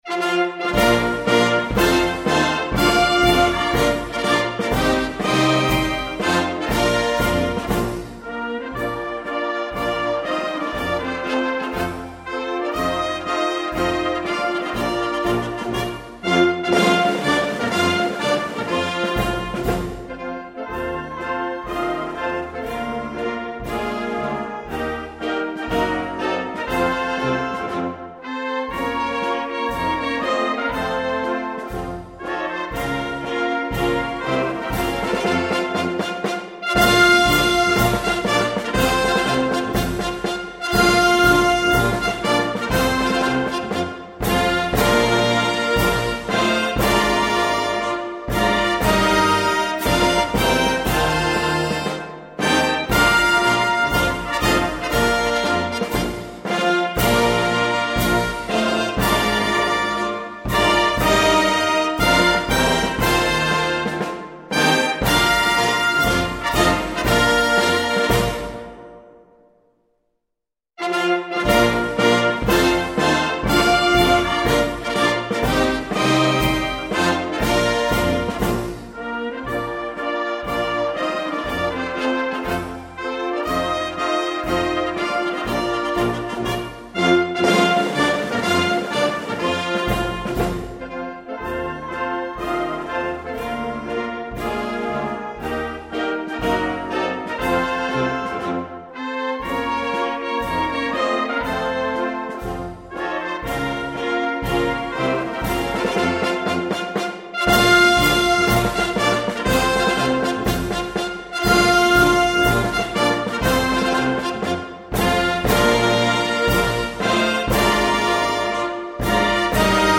Il nostro Concerto Bandistico ha sicuramente lasciato il segno in questa speciale trasferta Francese suscitando  forti emozioni alla gente locale.